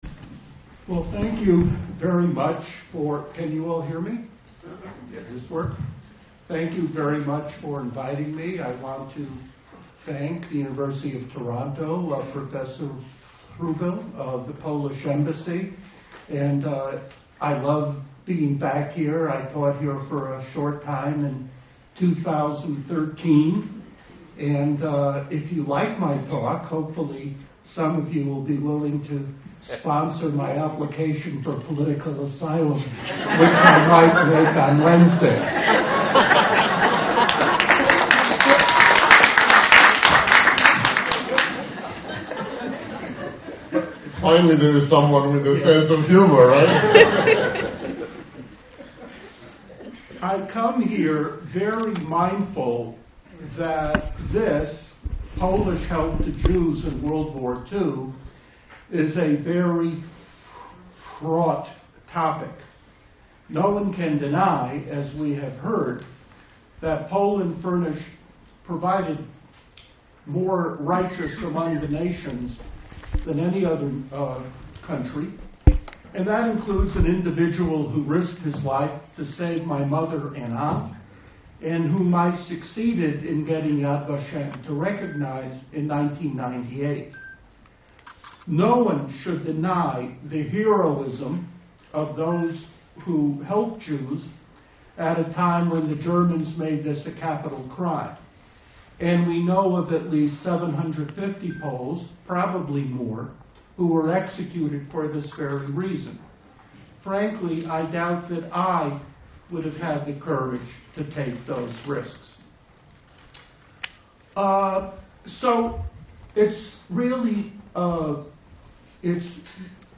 Presentations of the panelists